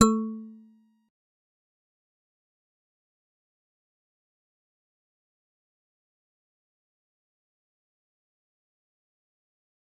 G_Musicbox-A3-pp.wav